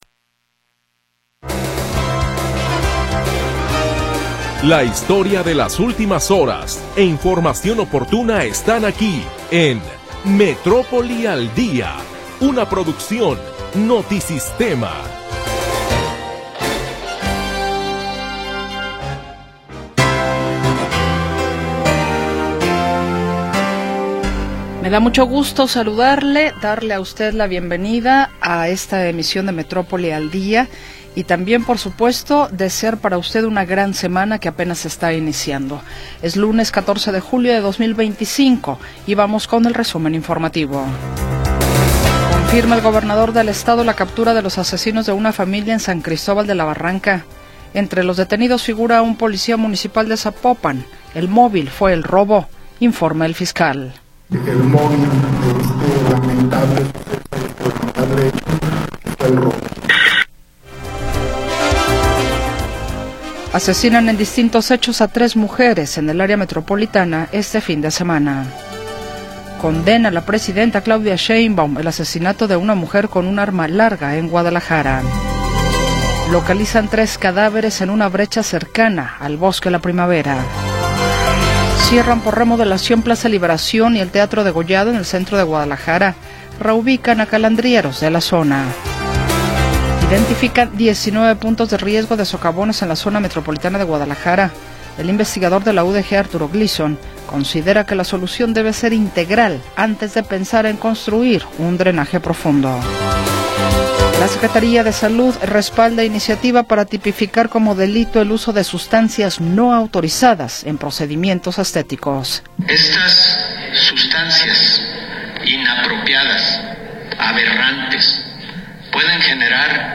Primera hora del programa transmitido el 14 de Julio de 2025.